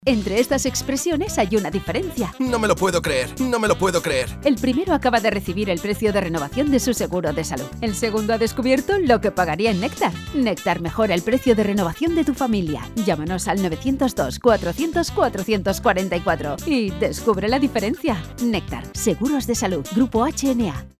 Cuñas